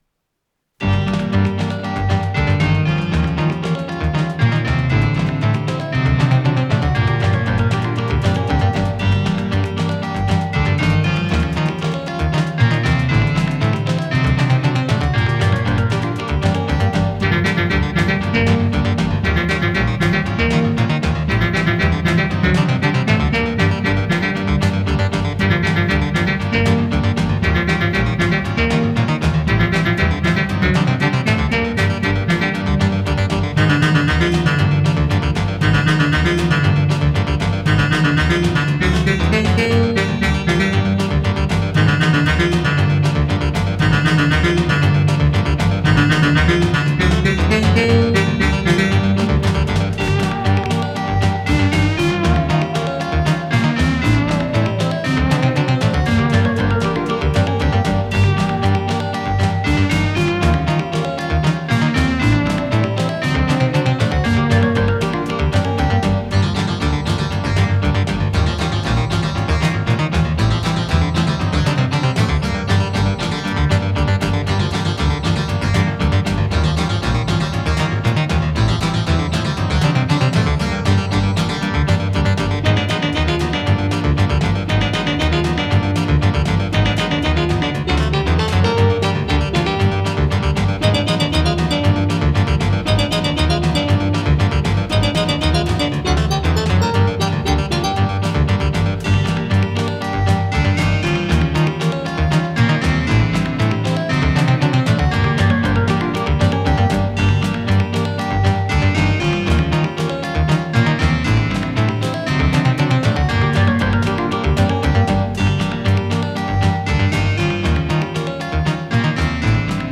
с профессиональной магнитной ленты
ПодзаголовокИнструментальная пьеса, соль минор
ВариантДубль моно